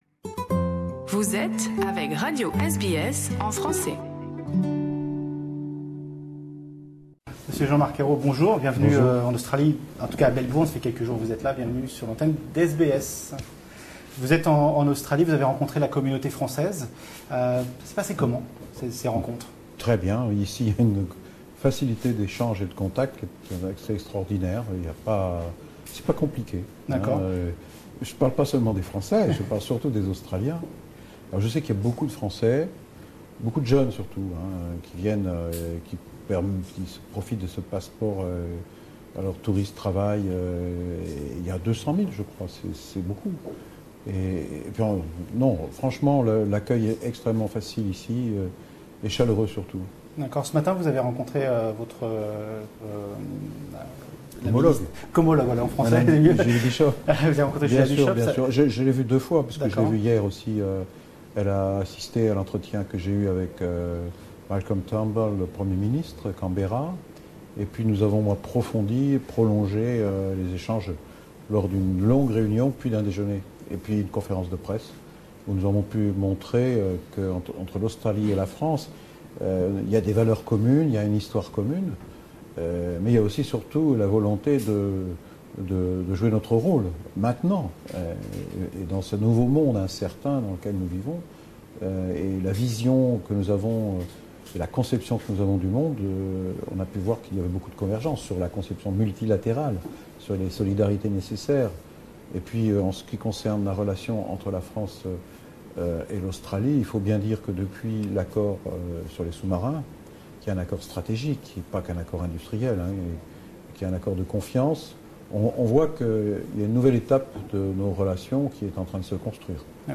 Interview exclusive avec Jean-Marc Ayrault